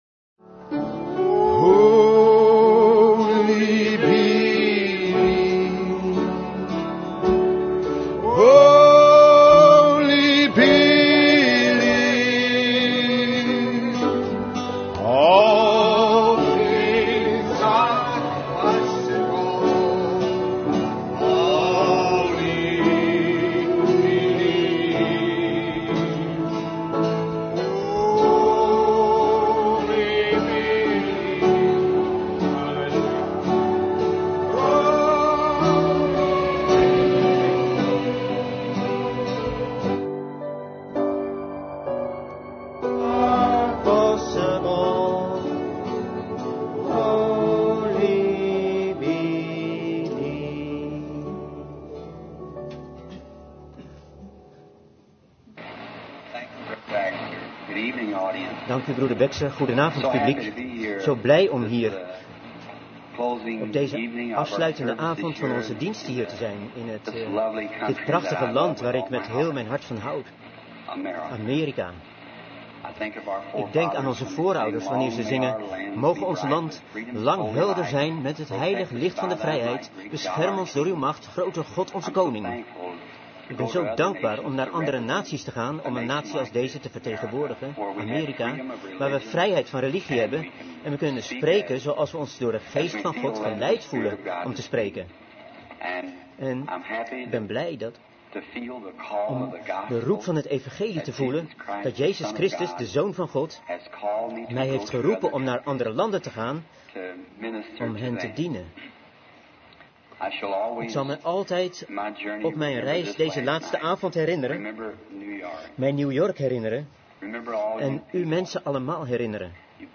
Vertaalde prediking "Expectations" door William Marrion Branham te Glad Tidings Tabernacle, New York, New York, USA, 's avonds op woensdag 05 april 1950